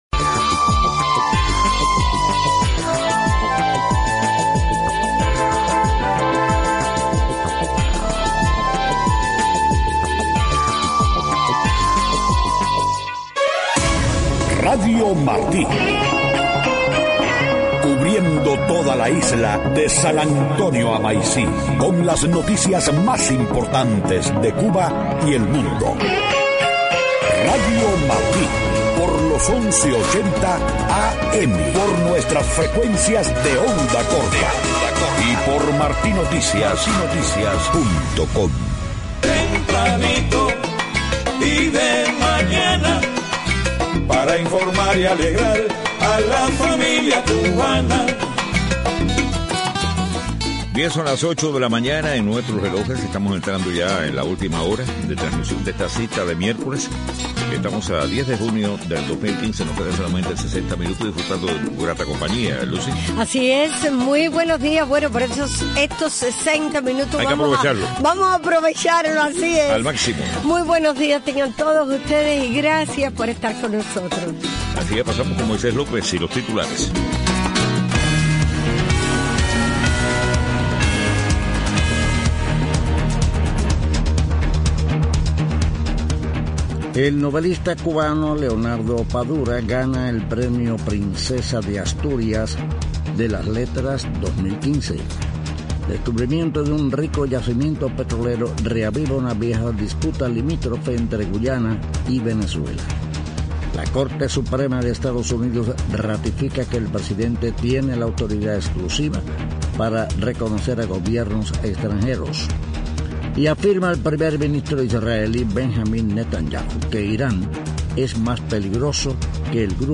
8:00 a.m. Noticias: Novelista cubano Leonardo Padura gana el premio Princesa de Asutrias de las Letras 2015.